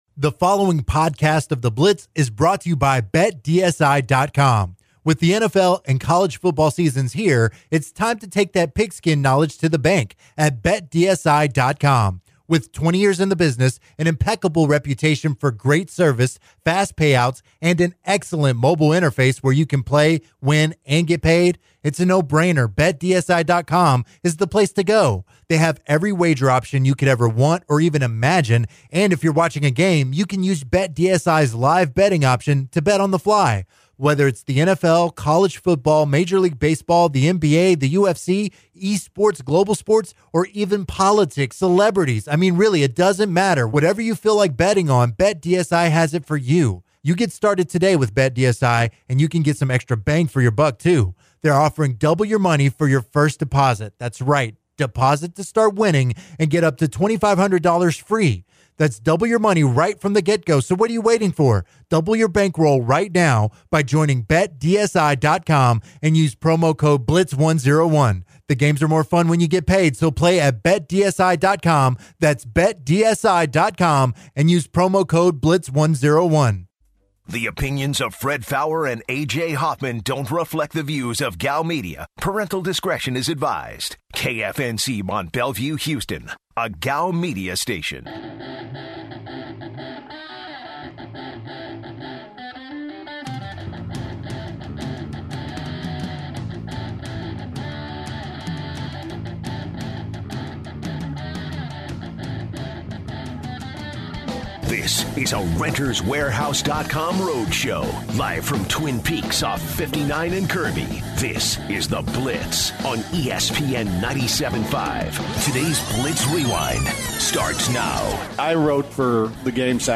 Live from Twin Peaks